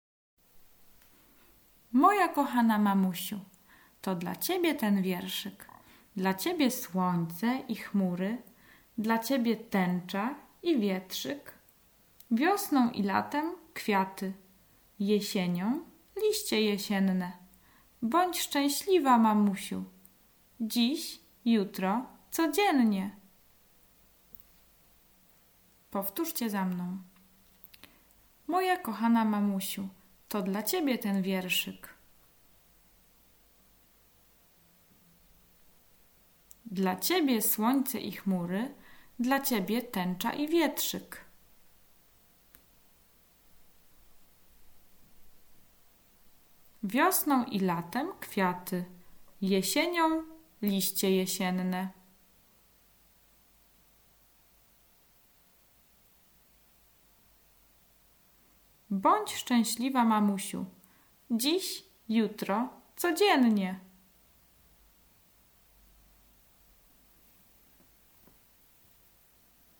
Poniżej znajdują się nagrania trzech krótkich wierszyków wraz z pauzami do nauki i powtarzania przez dziecko.